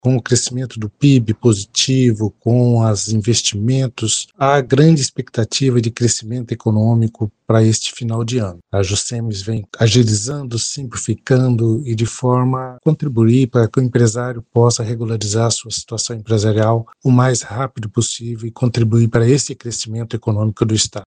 A equipe da FM Educativa MS conversou com o presidente da Jucems, Nivaldo Rocha.